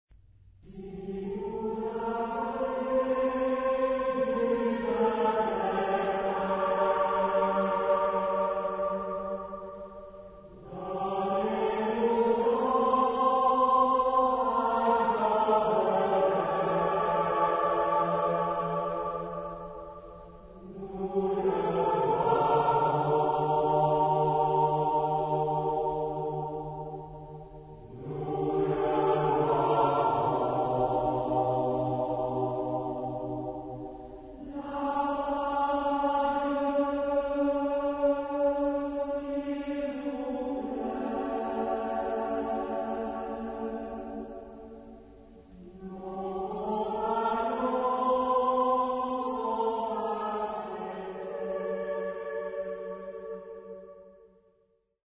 Genre-Style-Forme : Populaire ; Profane
Type de choeur : SAH OU SAT  (3 voix mixtes )
Tonalité : si bémol majeur